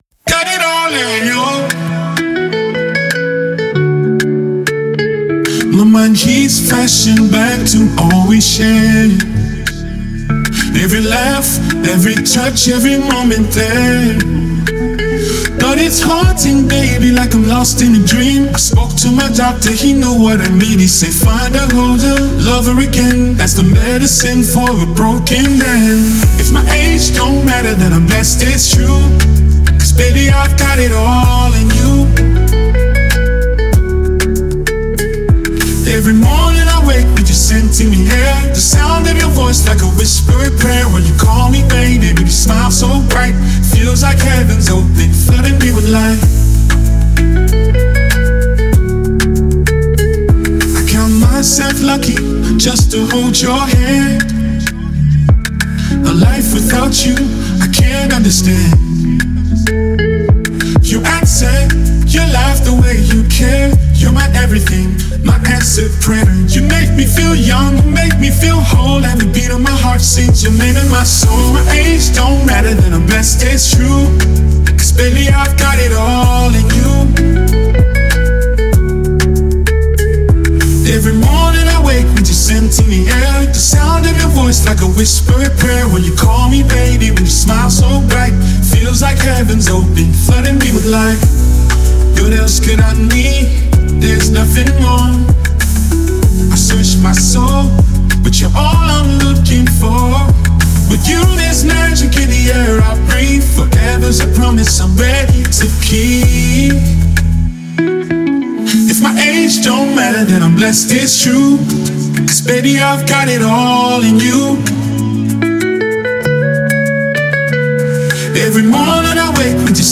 Nigeria Music
powerful voice
With its upbeat rhythm and motivational lyrics